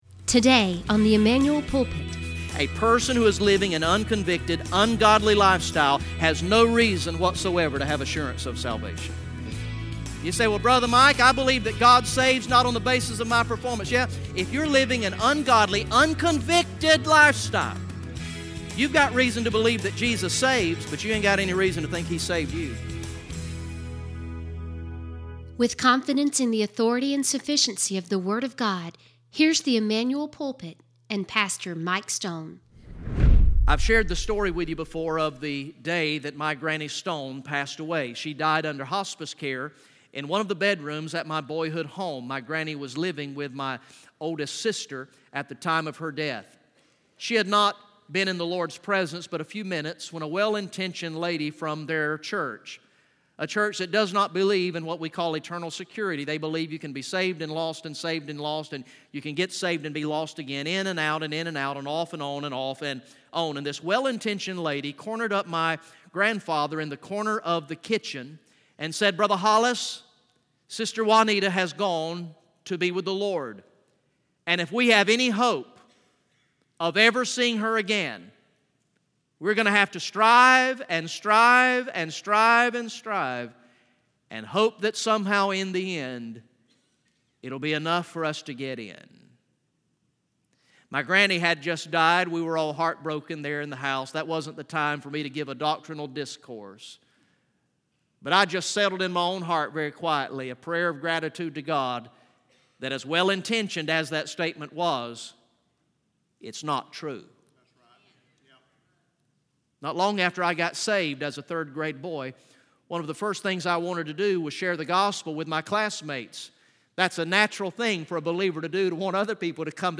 From the morning worship service on Sunday, July 1, 2018